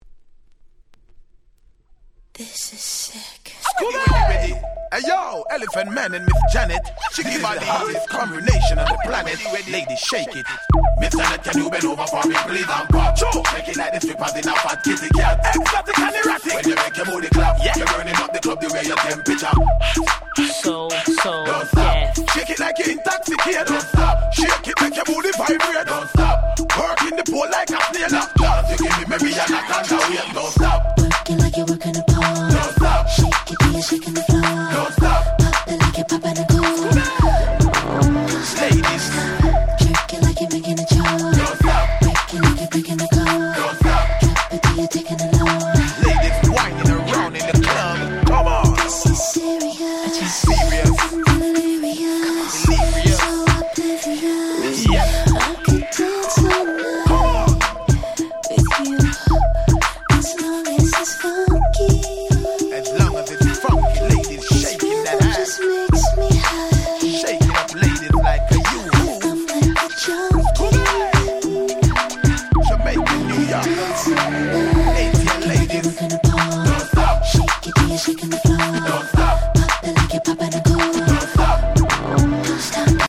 04' Smash Hit R&B.